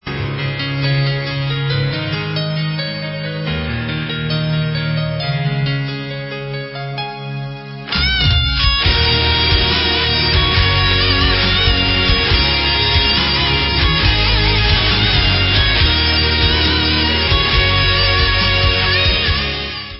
CZECH FEMALE FRONTED POWER METAL BAND